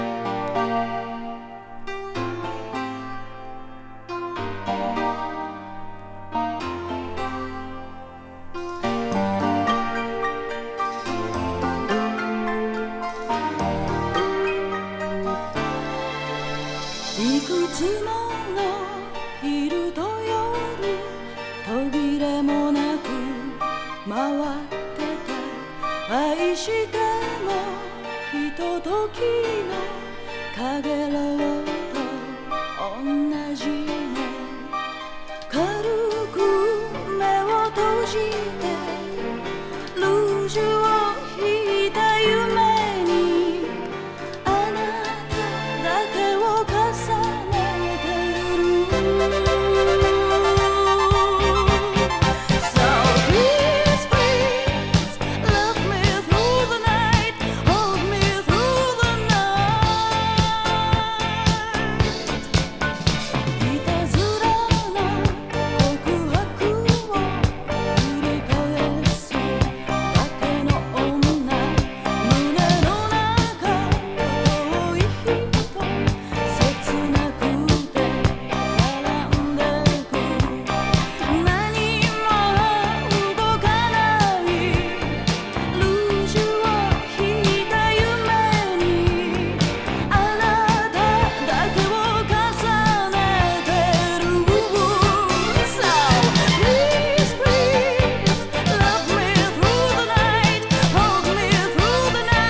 NW歌謡